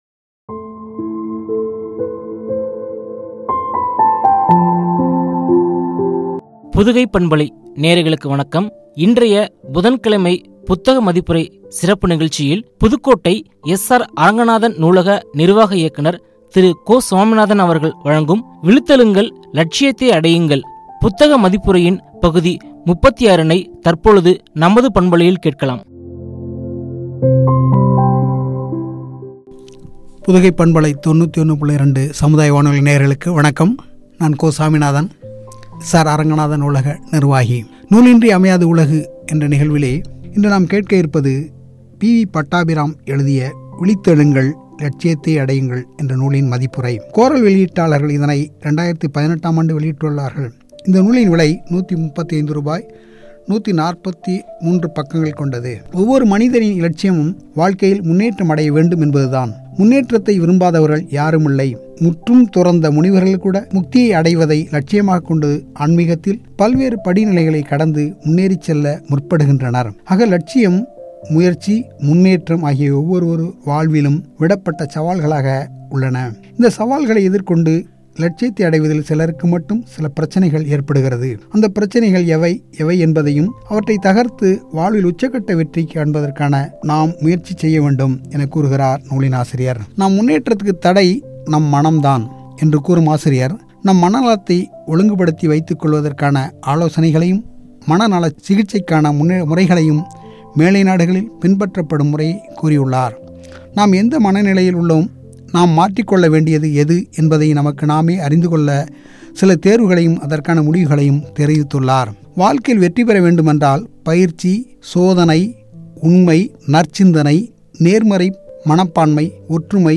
குறித்து வழங்கிய உரையாடல்.